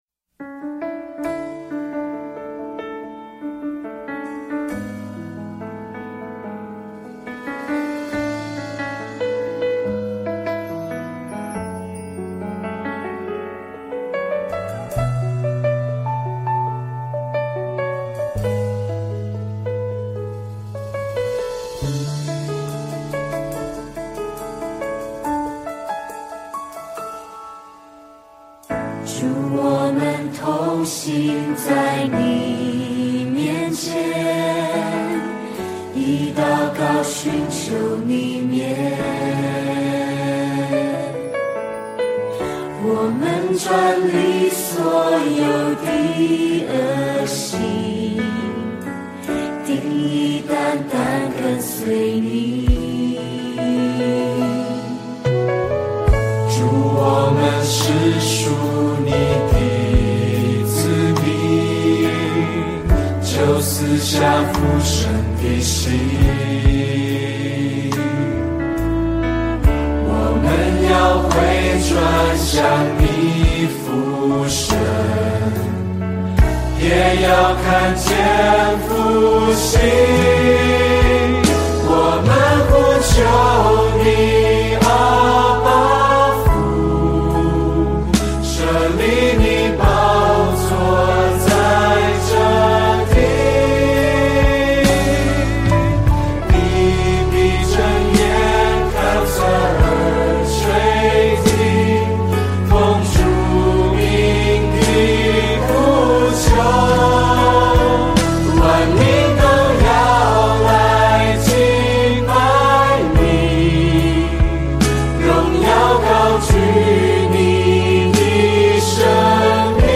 赞美诗 | 我们呼求